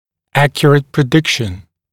[‘ækjərət prɪ’dɪkʃn][‘экйэрэт при’дикшн]точный прогноз